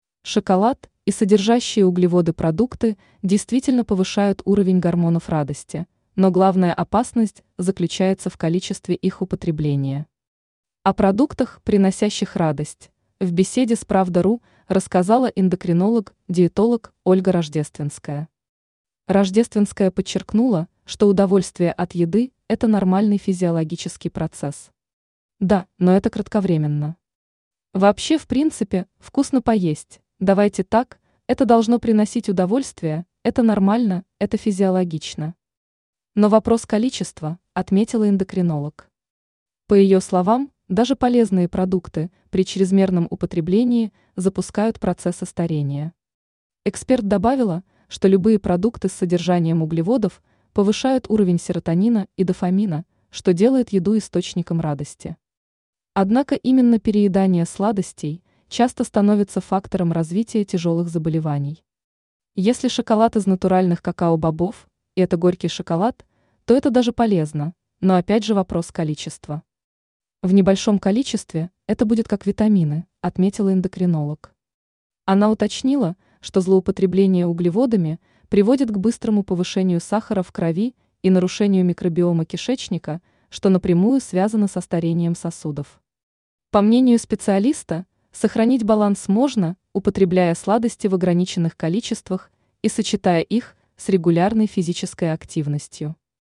скачать интервью в txt формате